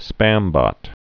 (spămbŏt)